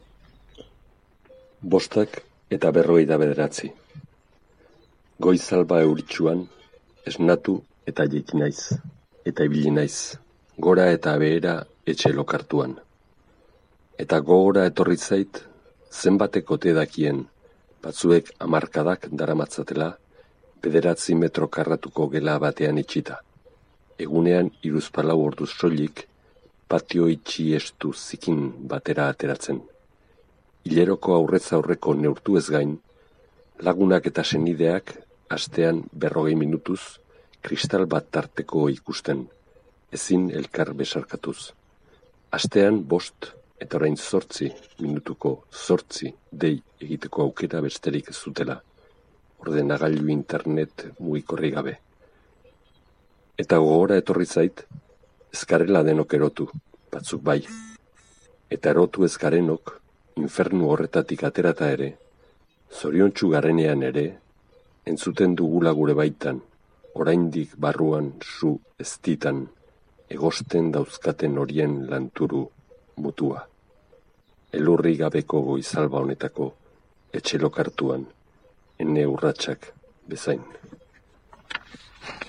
Gaurko saioan Mikel Albisuk (Mikel Antzak) bideratutako audio-testua ipini dugu. Konfinamenduan gauden garai hauetan espetxeko egoera ezin burutik kenduta eginiko hausnarketa saioa.